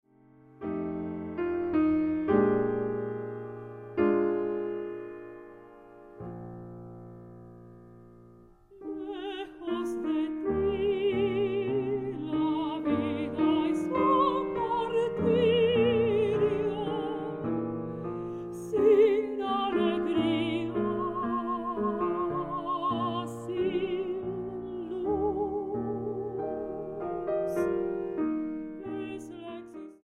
piano
Grabado en la Sala Nezahualcoyotl, febrero y octubre de 1998